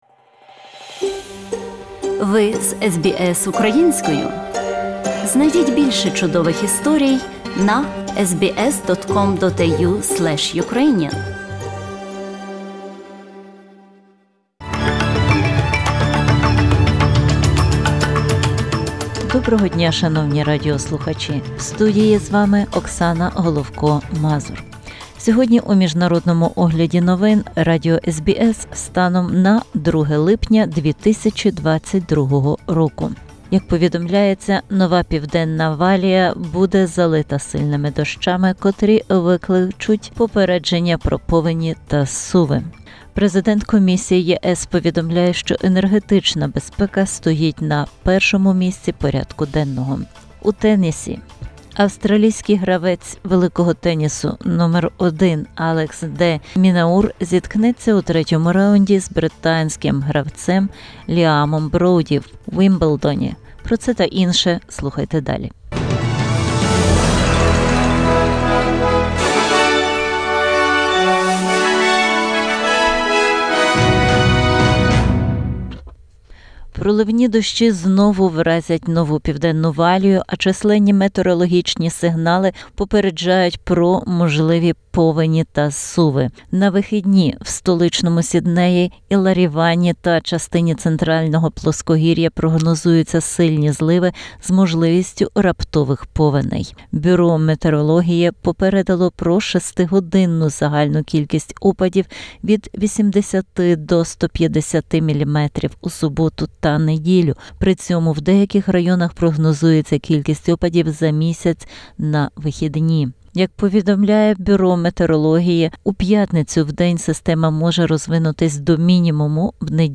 SBS News in Ukrainian - 2/07/2022